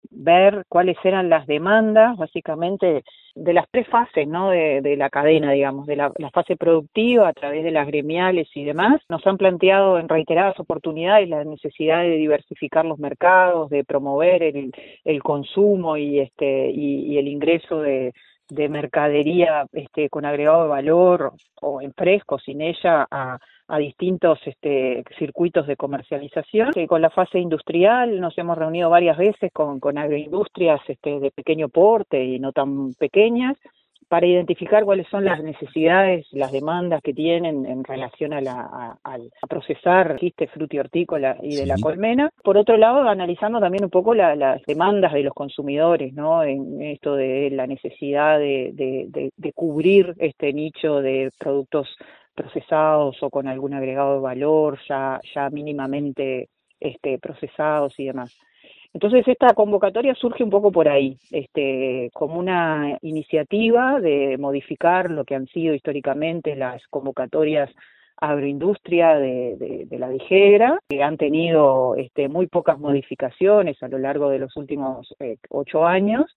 La directora General de la Granja, Laura González, explicó de qué se trata la iniciativa y cómo se hace para postular.